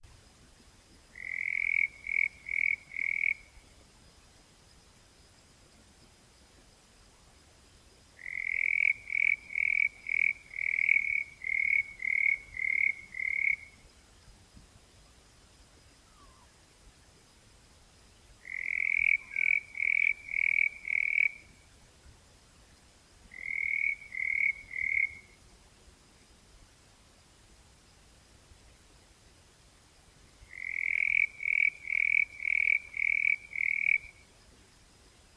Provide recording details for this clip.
The recording below (assumed to be of the photographed species) was made on a very frosty early morning, so it may well have been feeling a bit sluggish!